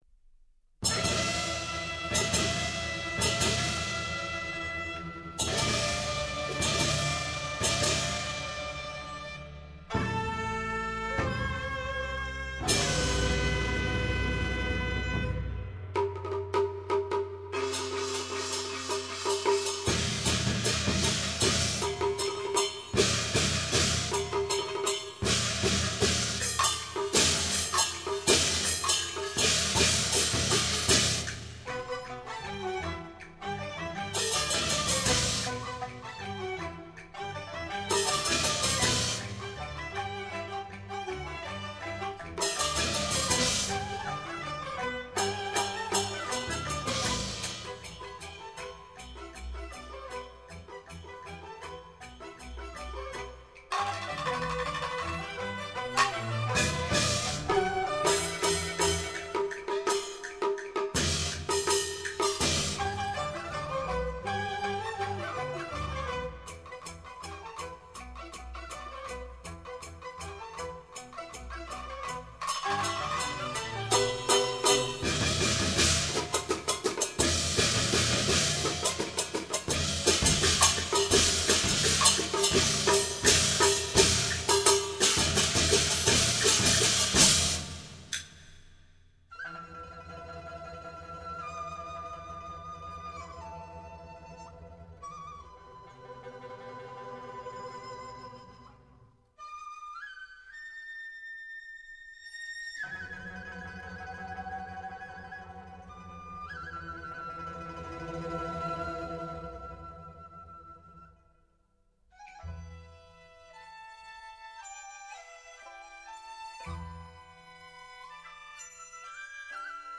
吹打乐